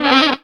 TRILLER SAX.wav